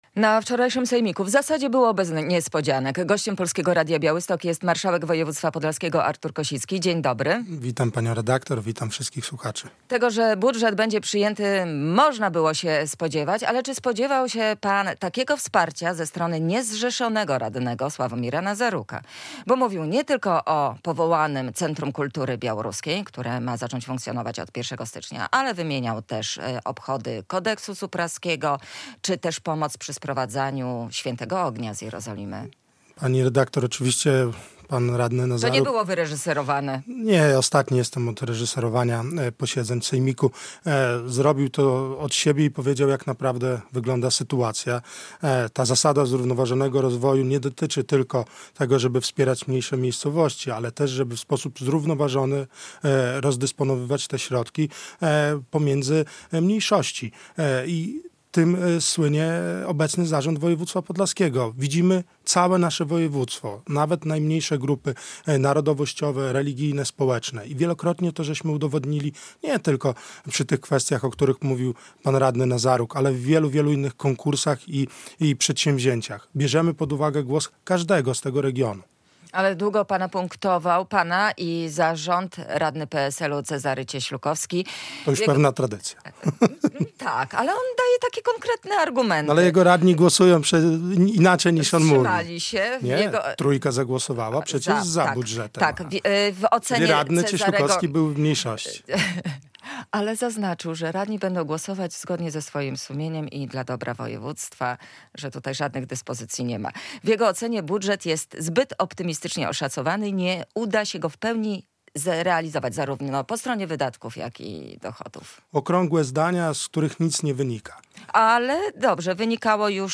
marszałek województwa podlaskiego